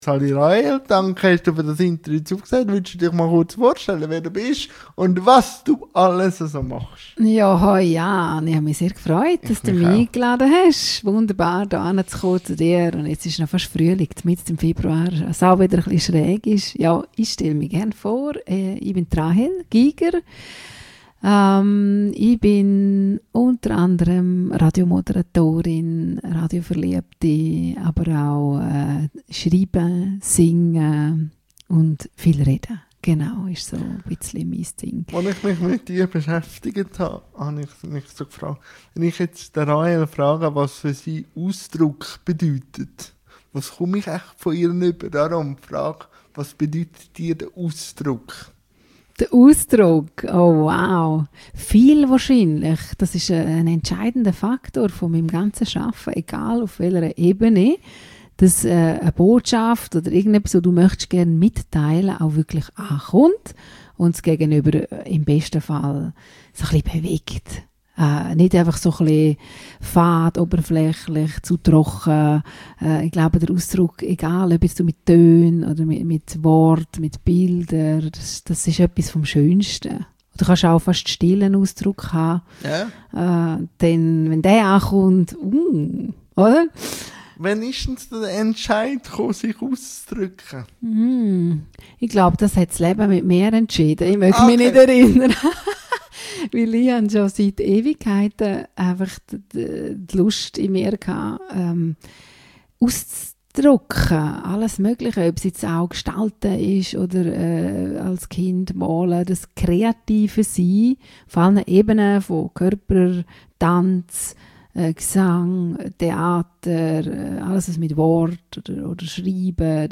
INTERVIEW-THEMEN | Ausdruck, Kunst u. v. m LINKS